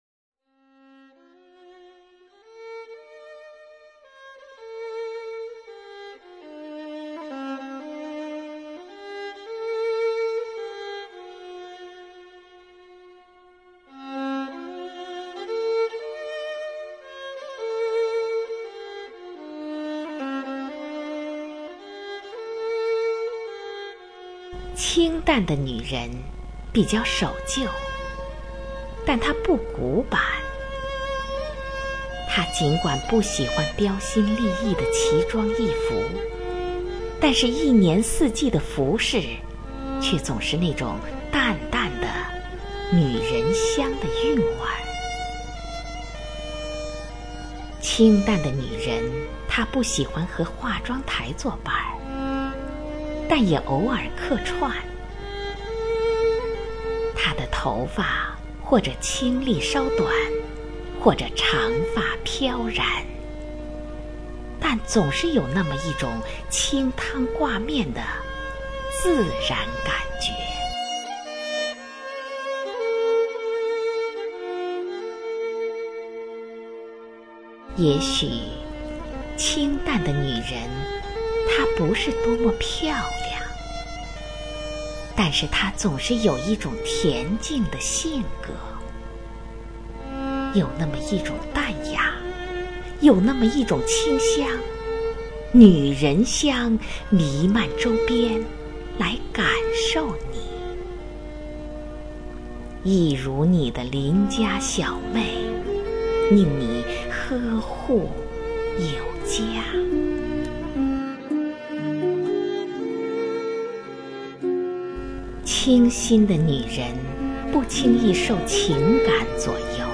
清淡的女人　　朗诵:未知